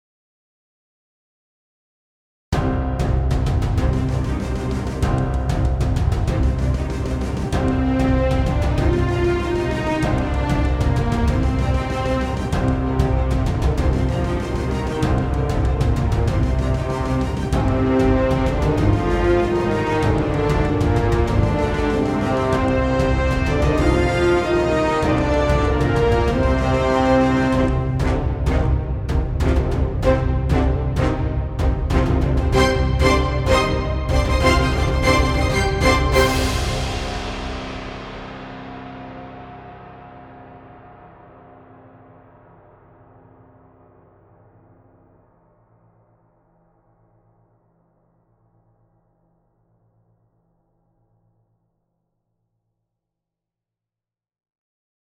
6 Souls   Bumper Music